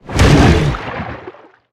Sfx_creature_snowstalker_flinch_swim_02.ogg